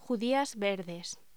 Locución: Judías verdes